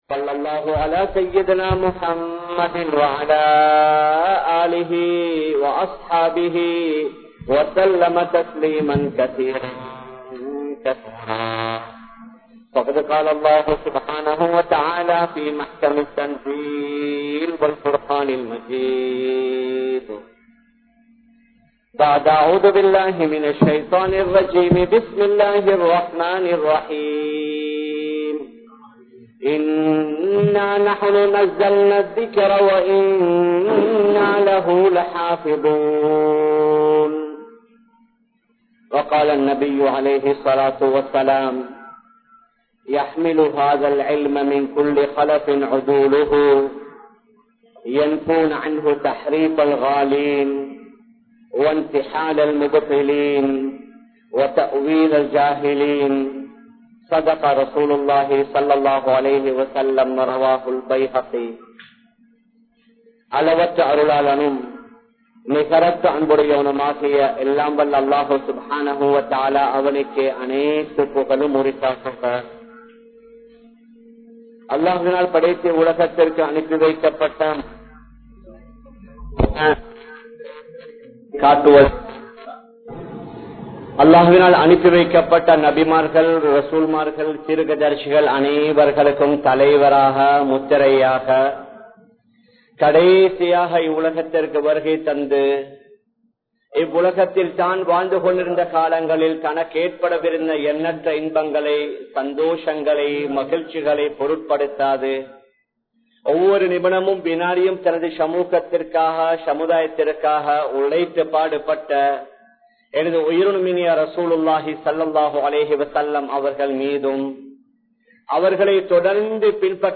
Em Samooaththil Ulamaakkalin Nilamai (எம் சமூகத்தில் உலமாக்களின் நிலமை) | Audio Bayans | All Ceylon Muslim Youth Community | Addalaichenai
Colombo 03, Kollupitty Jumua Masjith